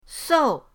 sou4.mp3